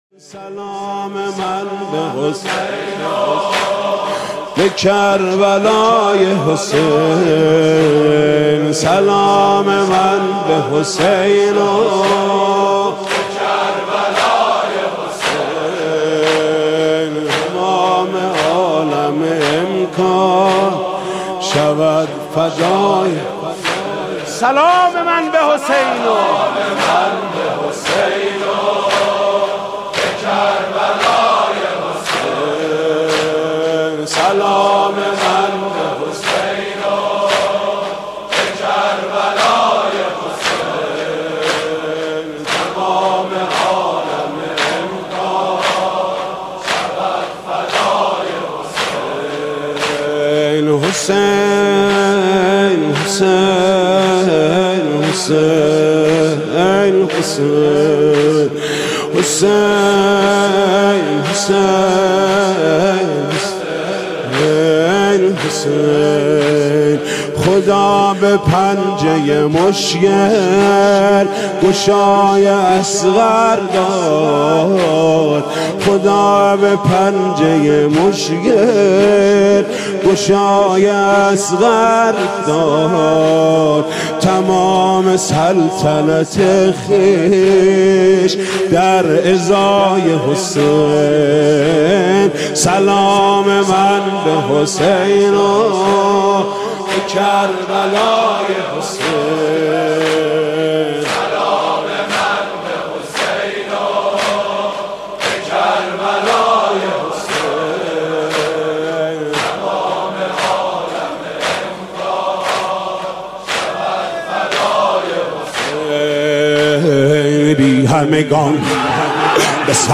متن نوحه